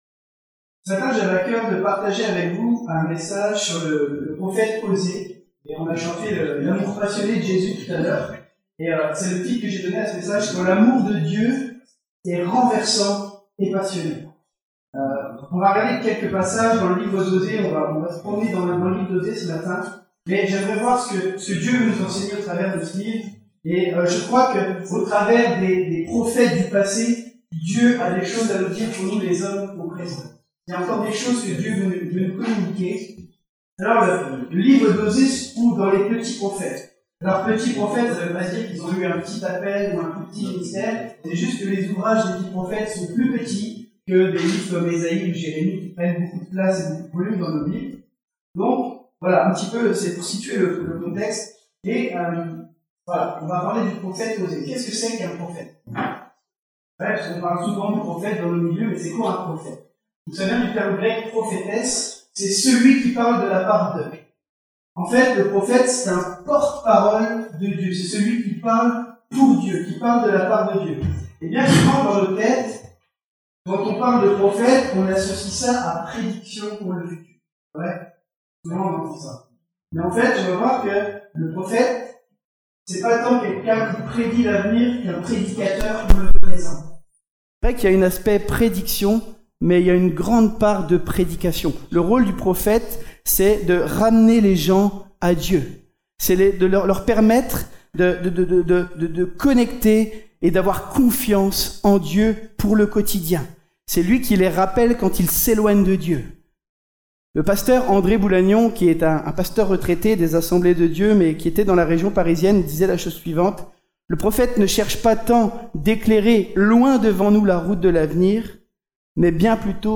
⚠ Problème de son jusque 01:20